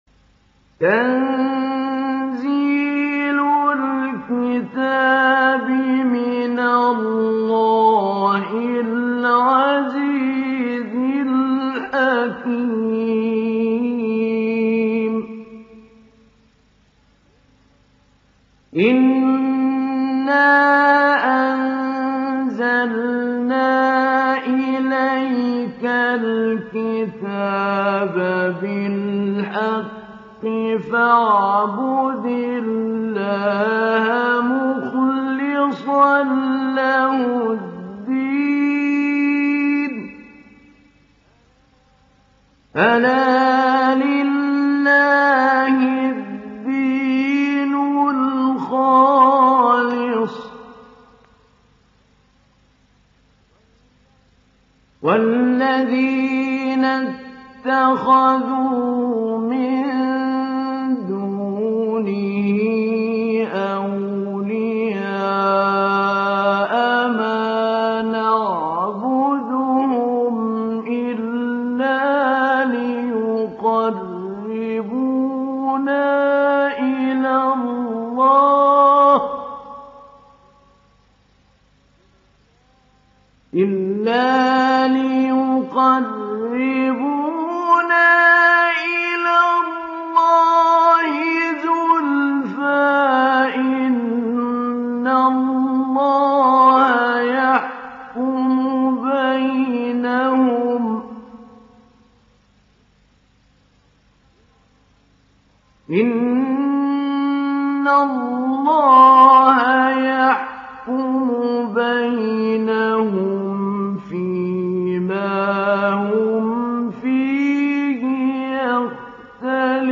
Surah Az zumar Download mp3 Mahmoud Ali Albanna Mujawwad Riwayat Hafs from Asim, Download Quran and listen mp3 full direct links
Download Surah Az zumar Mahmoud Ali Albanna Mujawwad